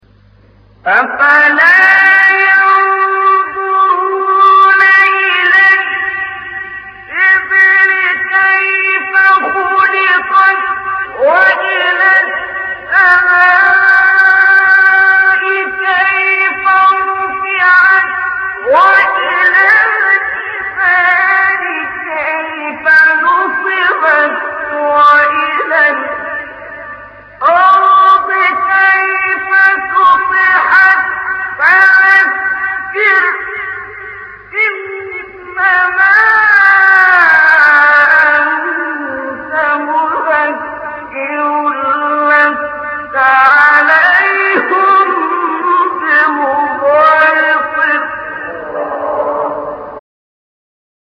سایت قرآن کلام نورانی -حجاز عبدالباسط ).mp3
سایت-قرآن-کلام-نورانی-حجاز-عبدالباسط.mp3